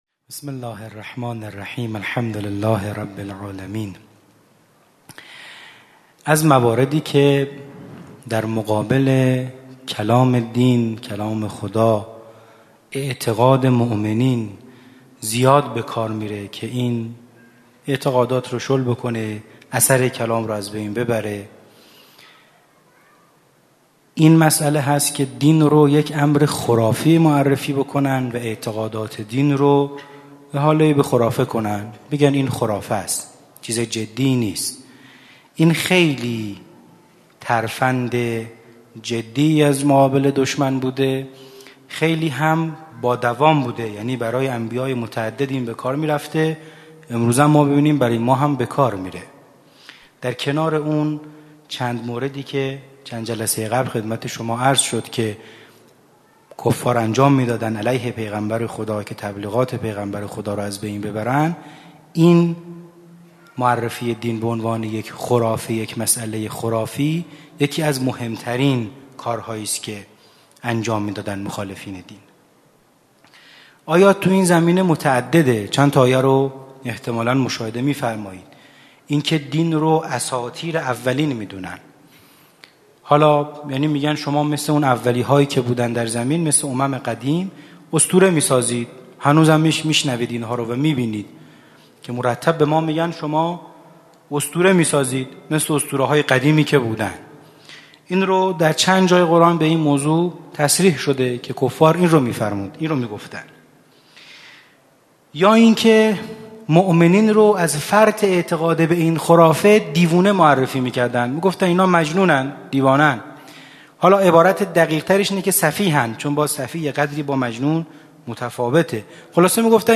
همچنین آیات استناد شده در خلال سخنرانی، توسط یکی از قاریان ممتاز دانشگاه به صورت ترتیل قرائت می‌شود.